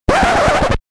Звук зажувало пленку.